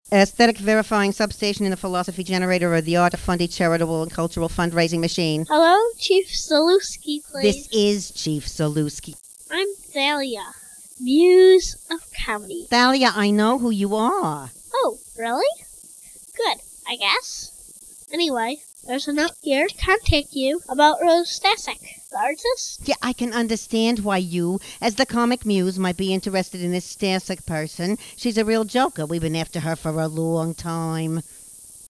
Chief Zalewski (mp3  700k)  (or RealAudio-68k), Sub-station bureaucrat trying to maintain standards at the Aesthetic Verifying Sub-station in the  Philosophy Generator of the Arta Fundi Charitable and Cultural Fund Raising Machine.
The dialogue is full of asides, quotes, poems, and sound effects as it coaxes, wheedles, challenges, reasons, denigrates, seduces, and whines attempting to increase the visitor's interaction and monetary contributions.